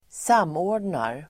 Uttal: [²s'am:å:r_dnar]